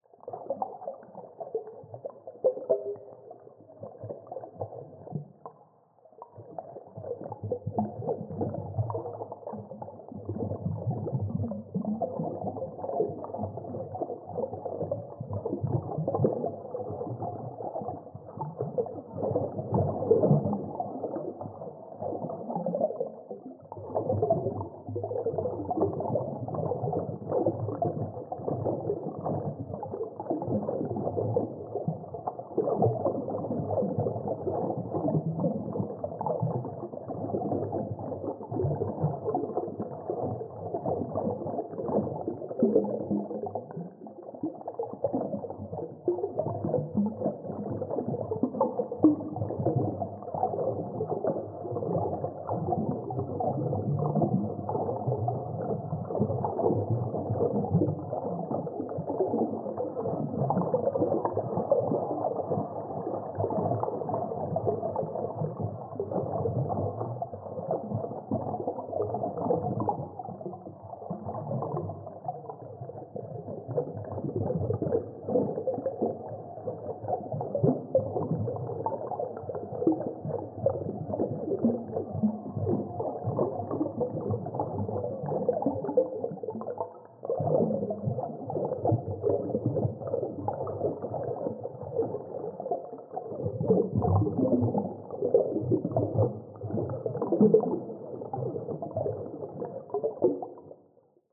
Dive Deep - Bubbles 03.wav